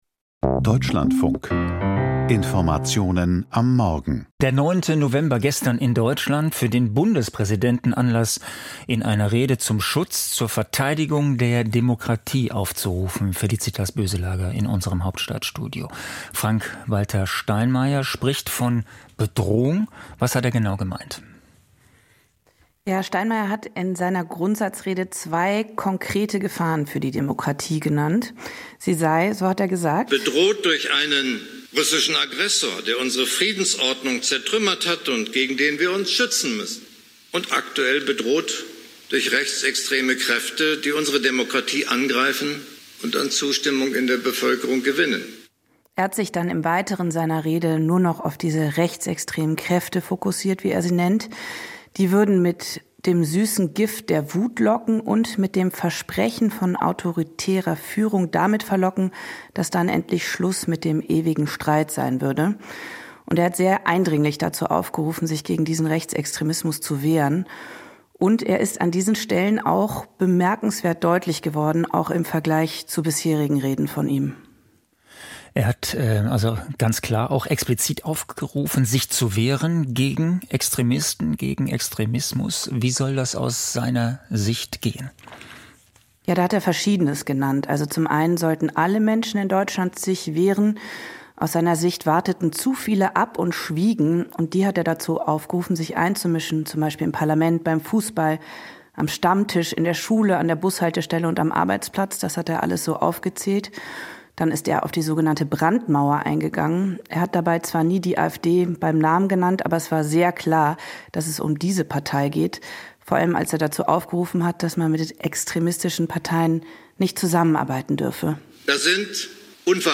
Grundsatzrede